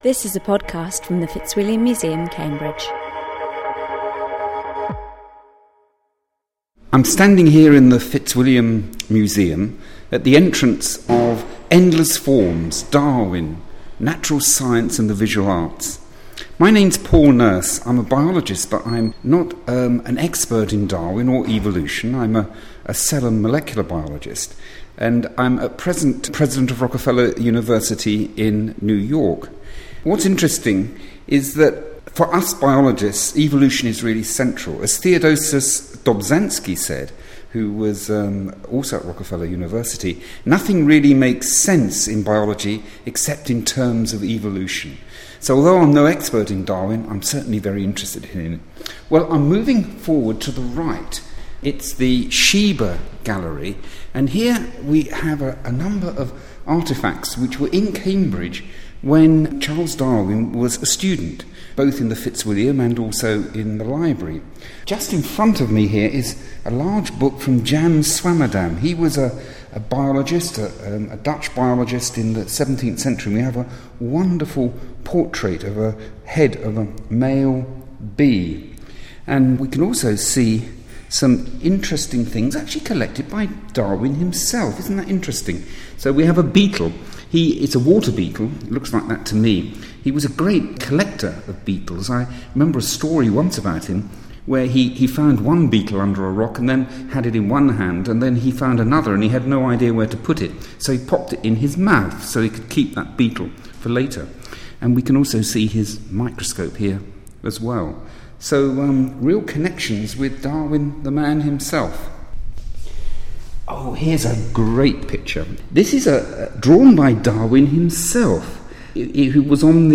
Nobel Prize-winning biologist and Rockefeller University President Sir Paul Nurse takes a tour of the exhibition 'Endless Forms', introducing his personal highlights from a scientist's perspective. (An audio-only podcast from the 2009 Cambridge Darwin Festival.)